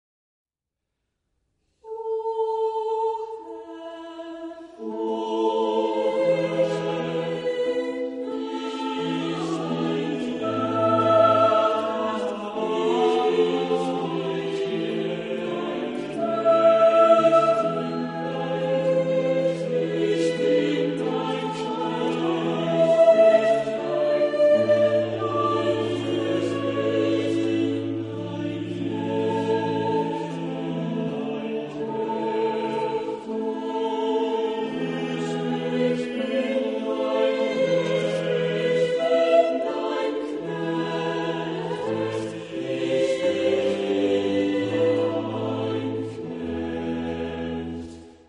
Genre-Stil-Form: geistlich ; Barock ; Motette
Chorgattung: SSATB  (5 gemischter Chor Stimmen )
Instrumentation: Continuo  (1 Instrumentalstimme(n))
Instrumente: Cembalo (1) oder Orgel (1)
Tonart(en): a-moll